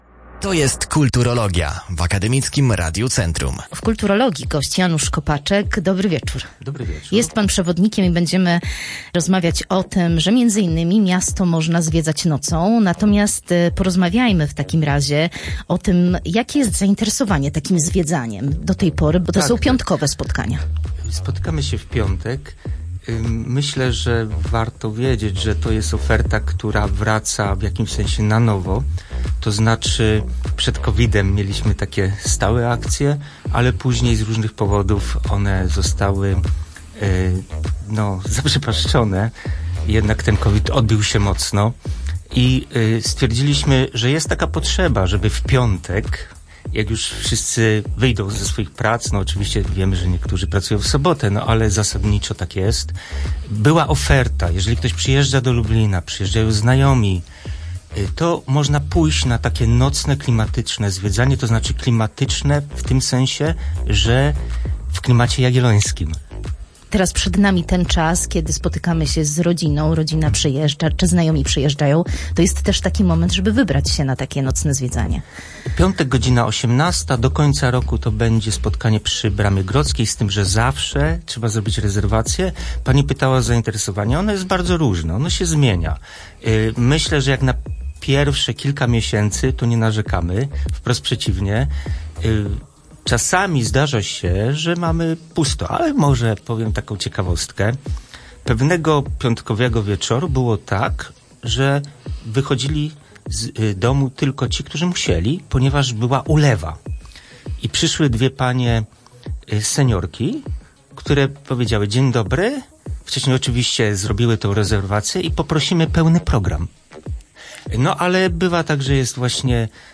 mówił w naszej rozmowie o Lublinie przewodnik